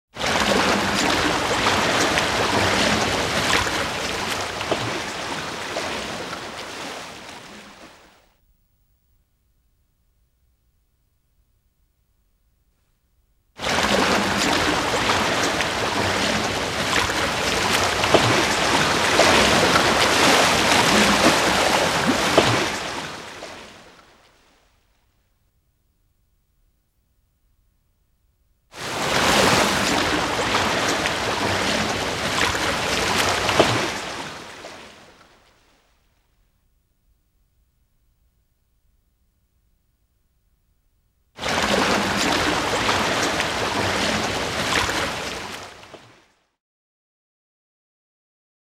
دانلود آهنگ وال 1 از افکت صوتی انسان و موجودات زنده
دانلود صدای وال 1 از ساعد نیوز با لینک مستقیم و کیفیت بالا
جلوه های صوتی